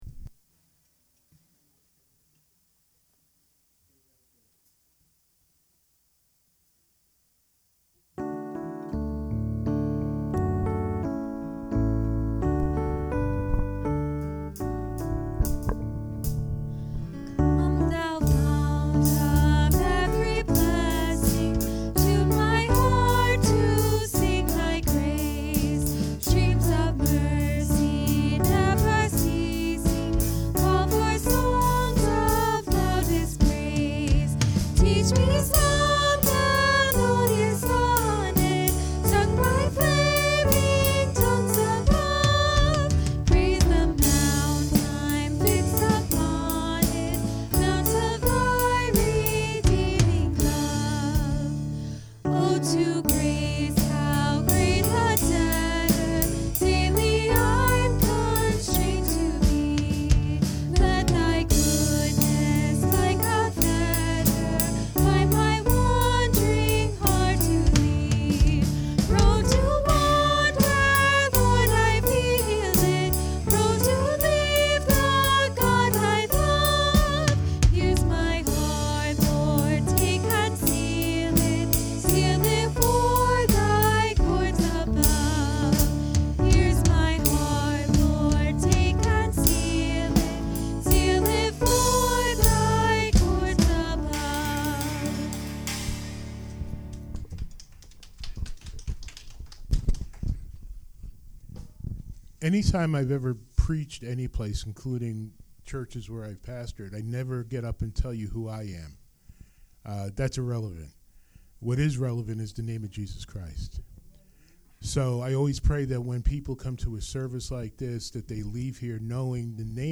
John 15:1-17 Service Type: Sunday Morning Worship Let me ask you a question this morning.